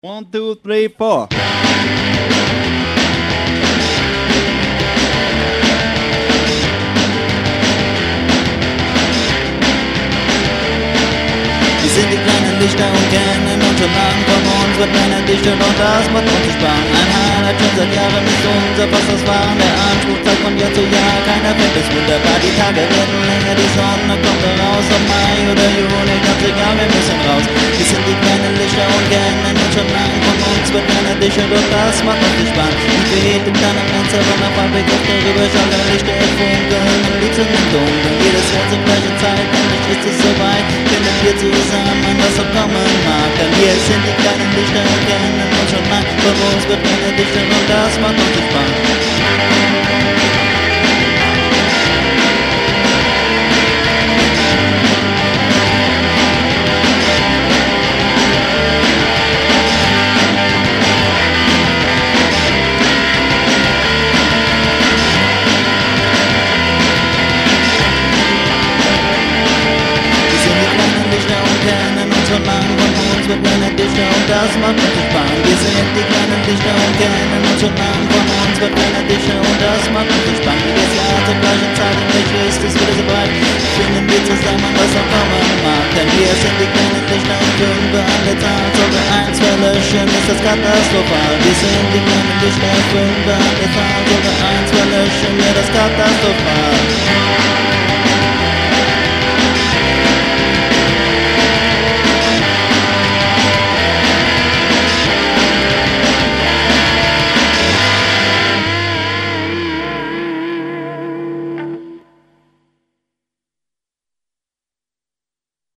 Garage Mix
Recorded at the Roof-Top-Chamber, Bad Ems 16.12.2000